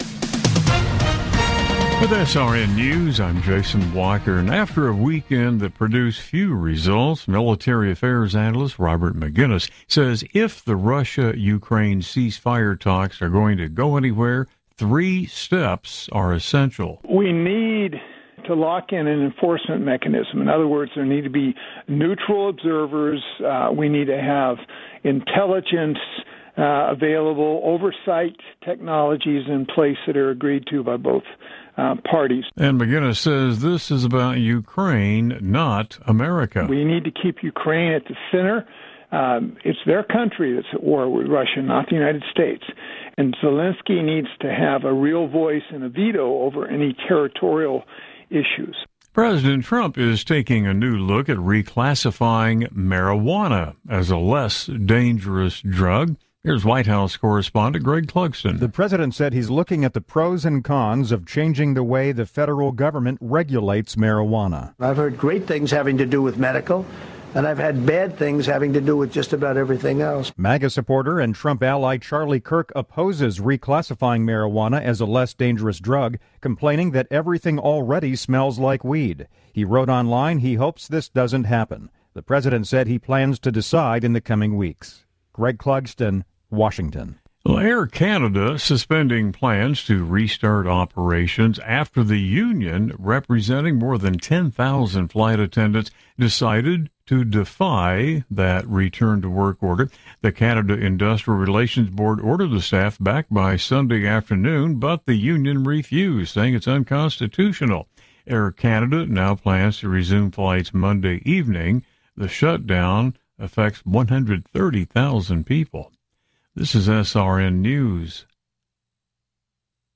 Top News Stories Aug 18, 2025 – 03:00 AM CDT